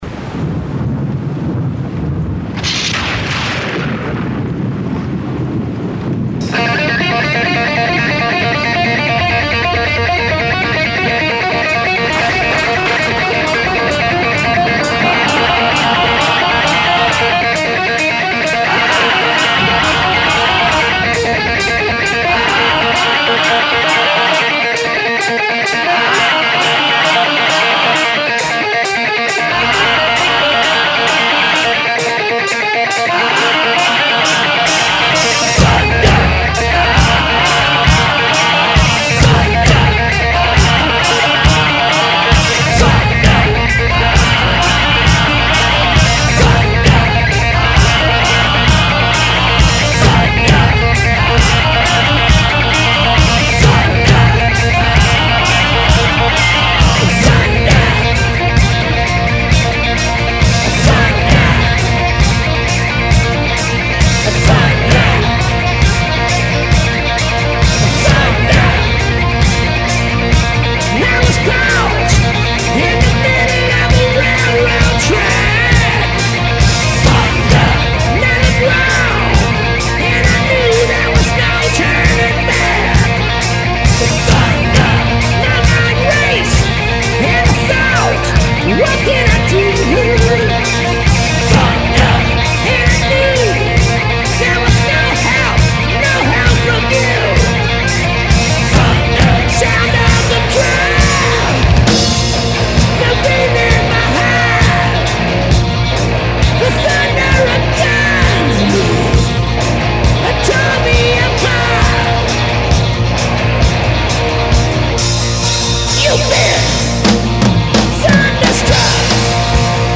LIVE AUDIO